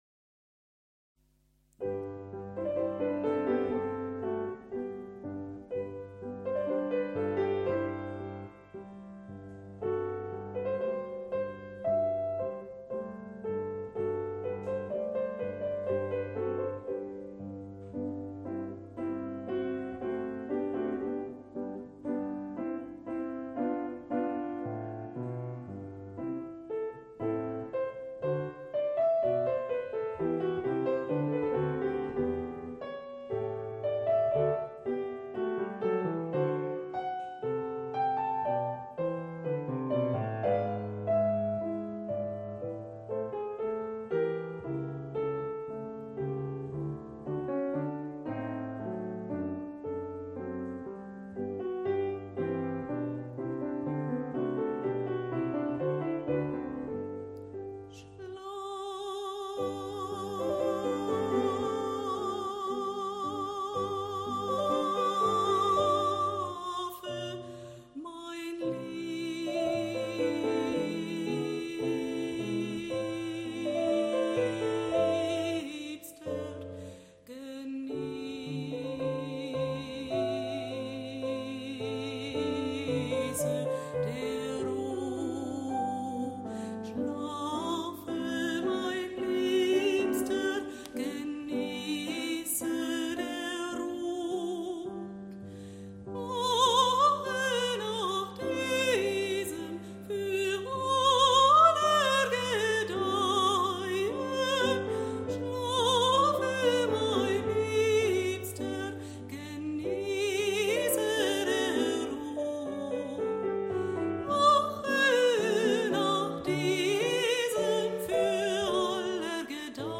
Sängerin
Klavier